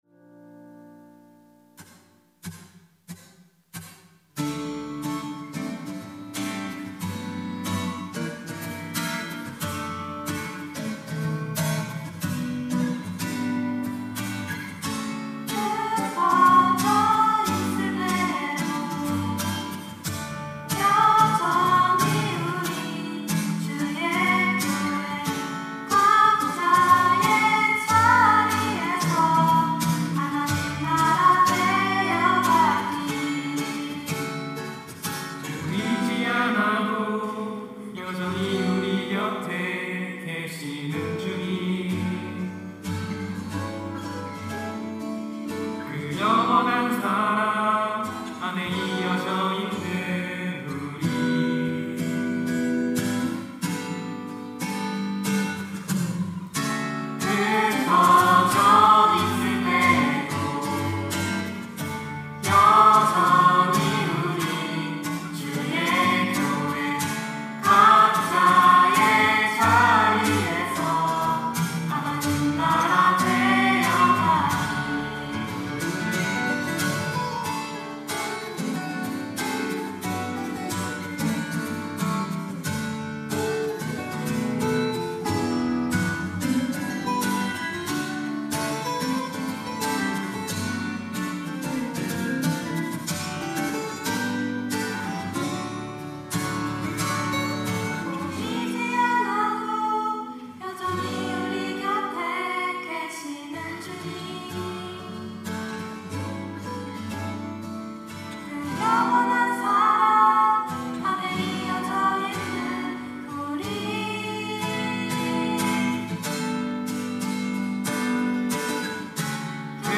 특송과 특주 - 여전히 우리는 교회